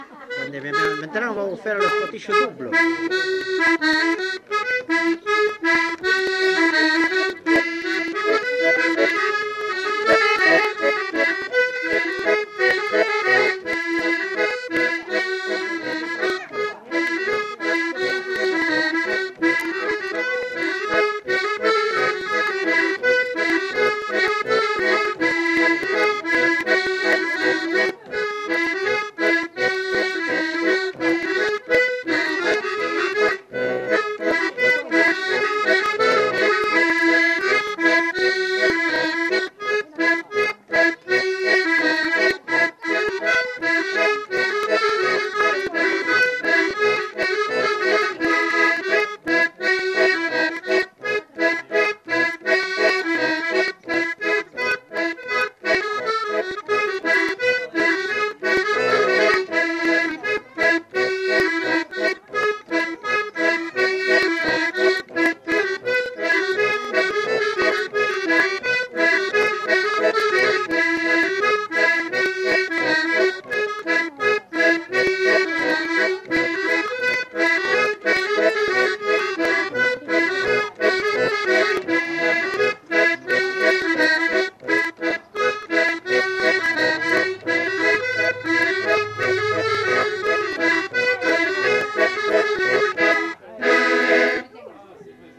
Scottish double